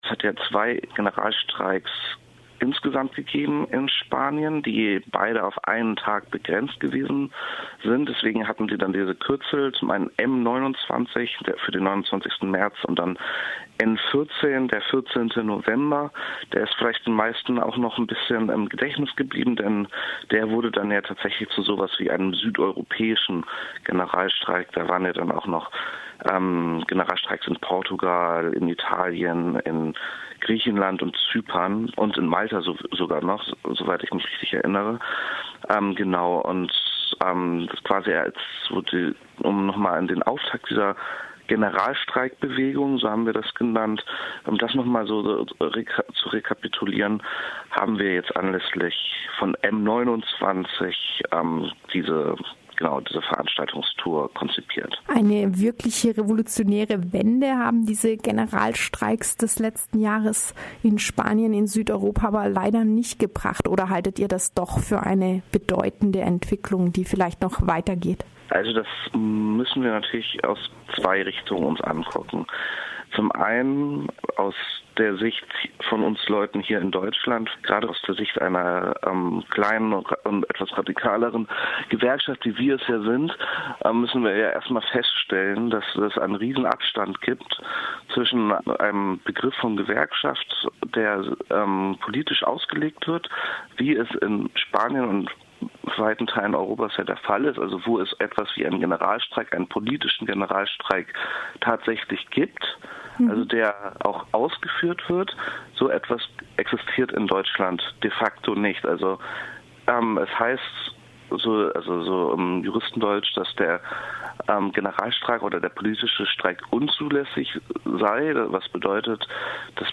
Vivir contra la crisis - Diskussion mit Aktivistinnen der spanischen Generalstreiksbewegung